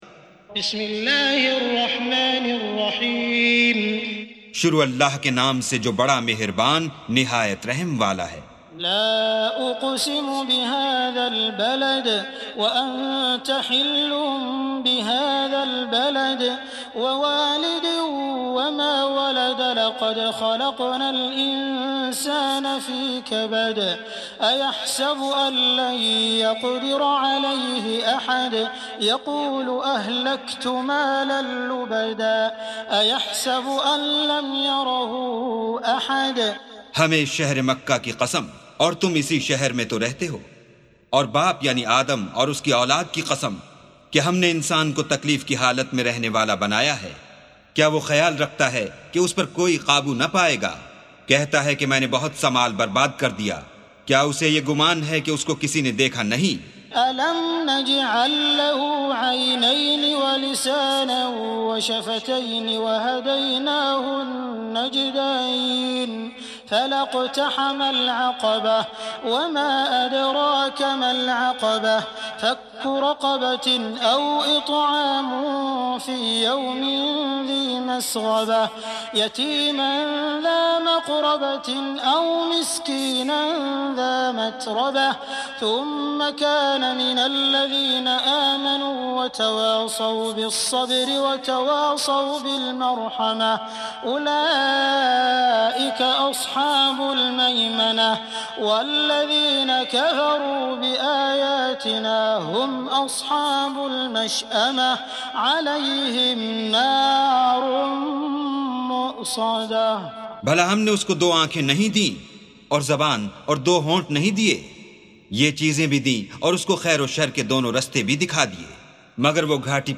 سُورَةُ البَلَدِ بصوت الشيخ السديس والشريم مترجم إلى الاردو